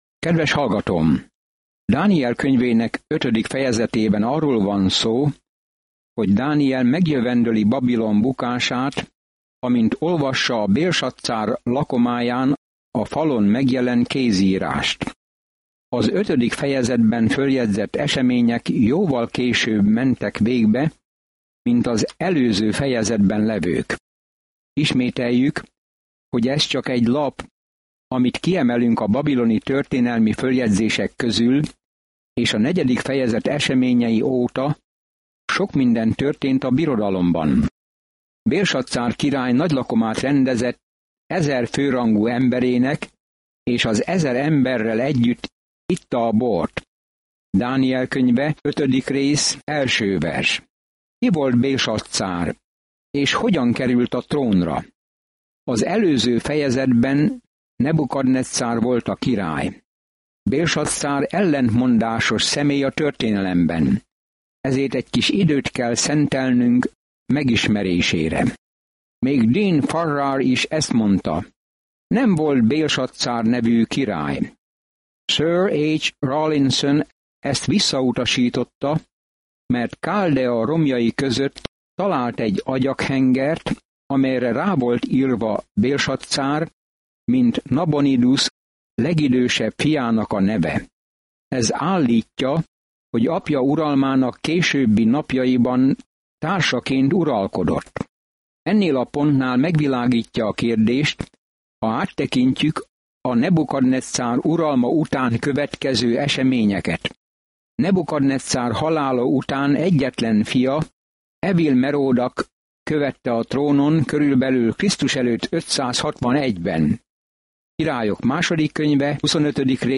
Szentírás Dániel 5:1-14 Nap 10 Olvasóterv elkezdése Nap 12 A tervről Dániel könyve egyszerre egy olyan ember életrajza, aki hitt Istenben, és egy prófétai látomás arról, hogy ki fogja végül uralni a világot. Napi utazás Dánielen keresztül, miközben hallgatod a hangos tanulmányt, és olvasol válogatott verseket Isten szavából.